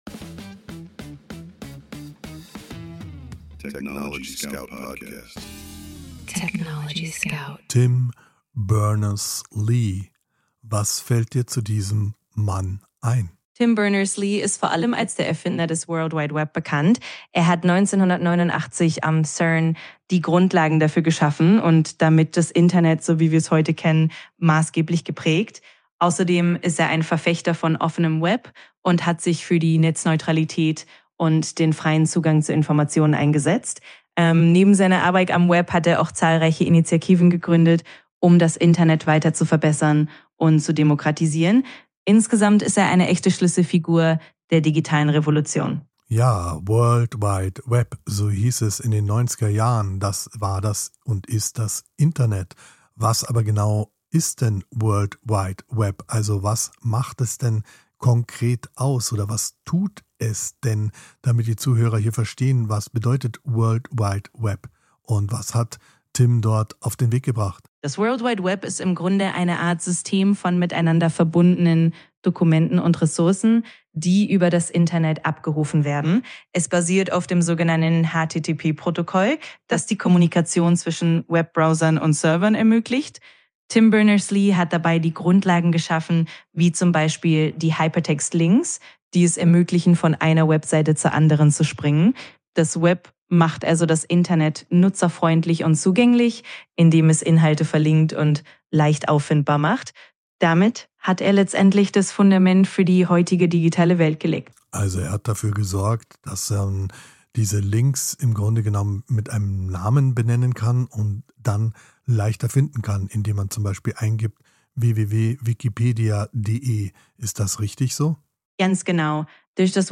gemeinsam mit seiner digitalen Co-Moderatorin ChatGPT jeden
Mensch und KI sprechen miteinander – nicht gegeneinander.